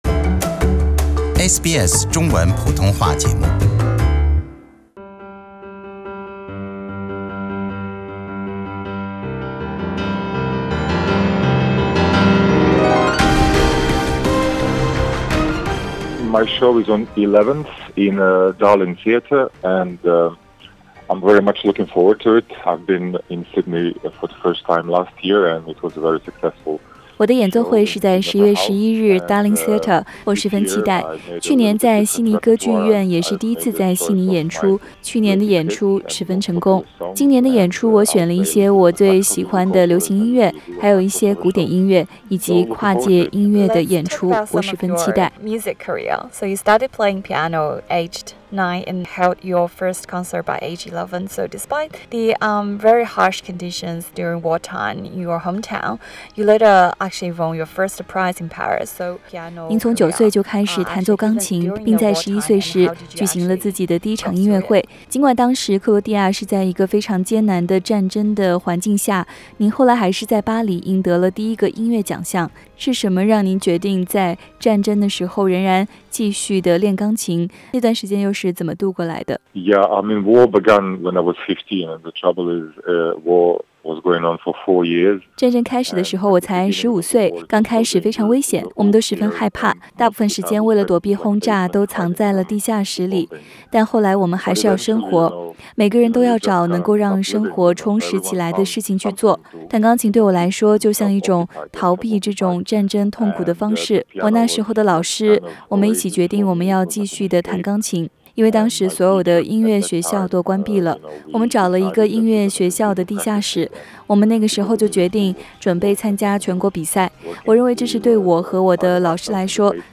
【专访】克罗地亚古典跨界钢琴演奏家马克西姆：1秒16键的"钢琴玩家"重新定义古典音乐